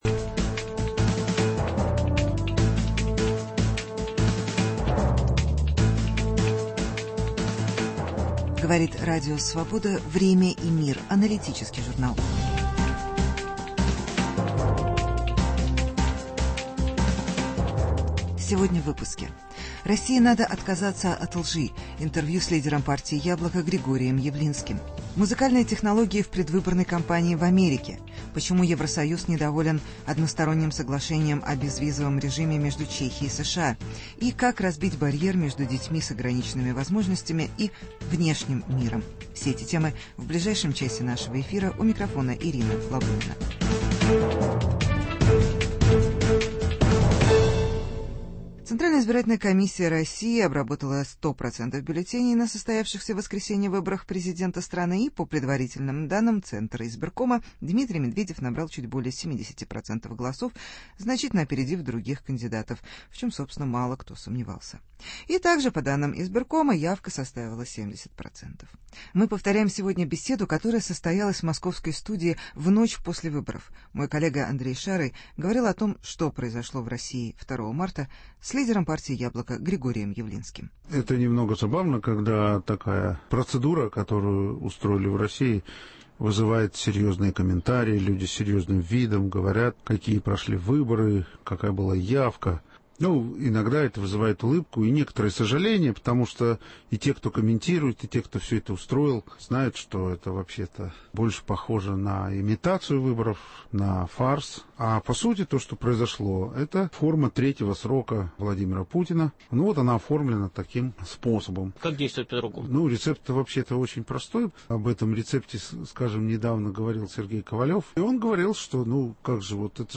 Интервью с Г. Явлинским о прошедших президентских выборах.